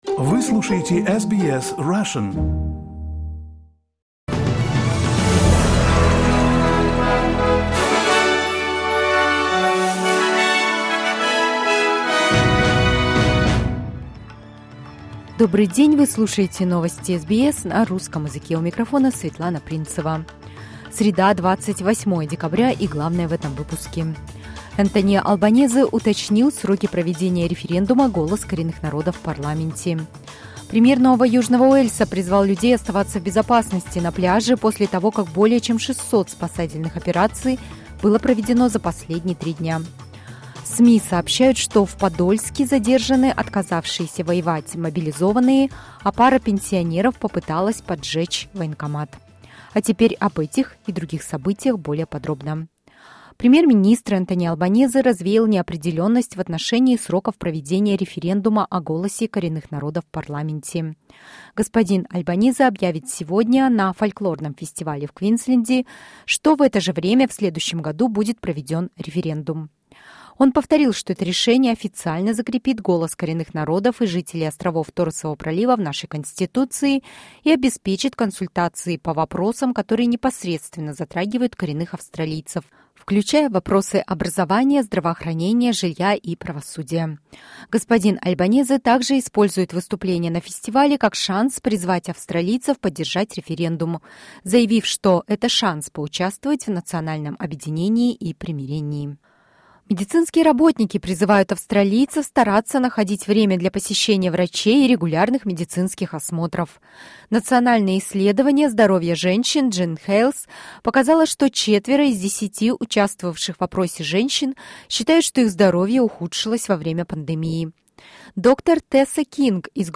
SBS news in Russian — 28.12.2022